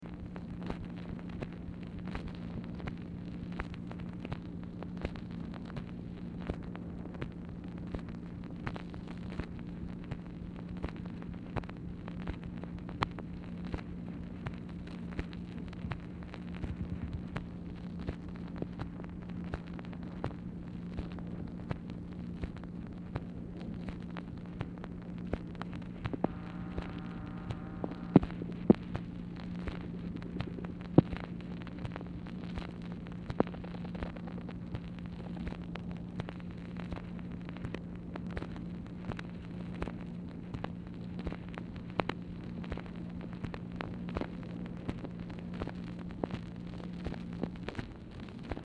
OFFICE NOISE
Oval Office or unknown location
Telephone conversation
Dictation belt